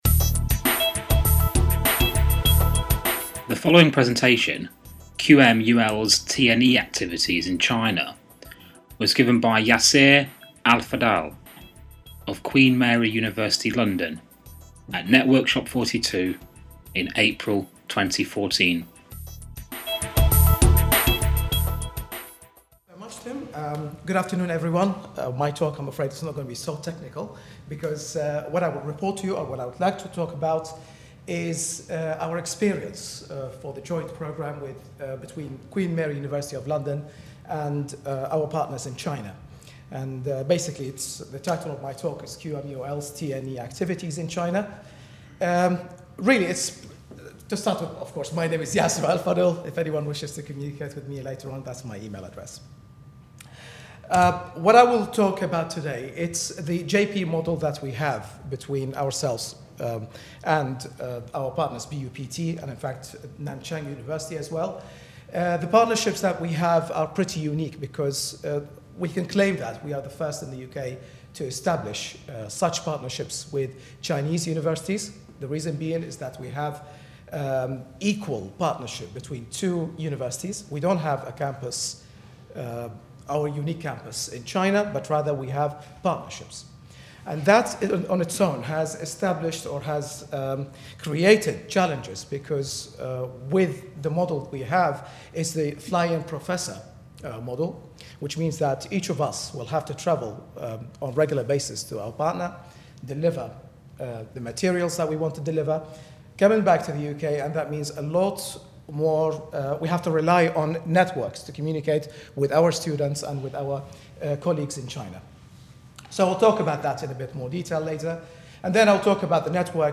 Networkshop 42